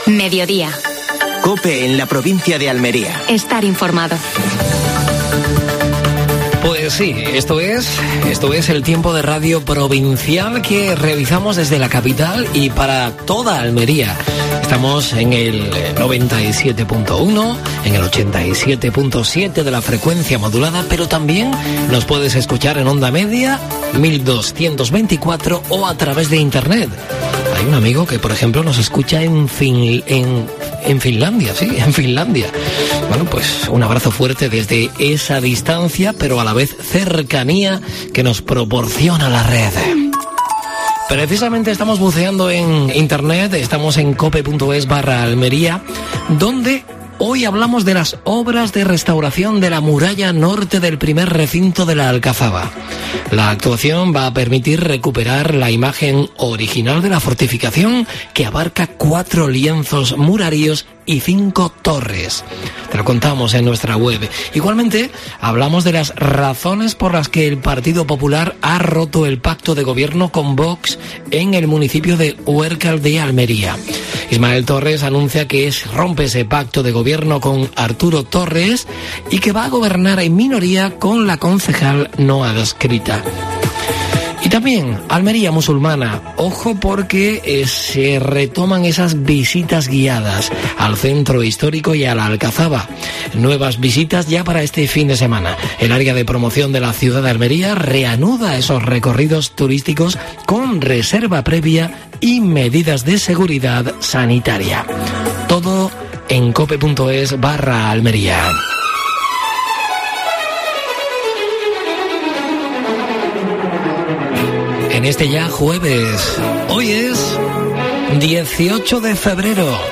Última hora deportiva.